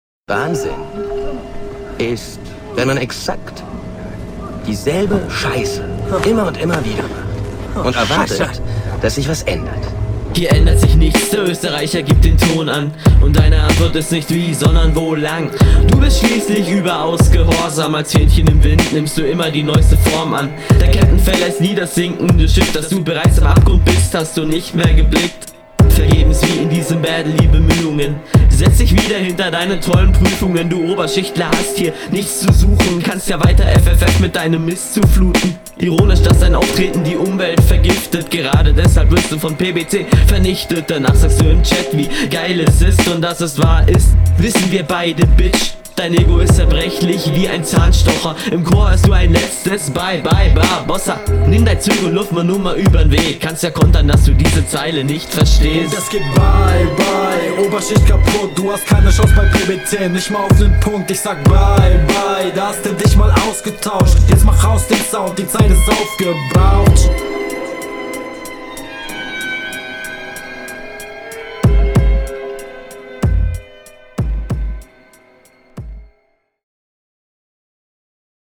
Ungewöhnlicher Beat.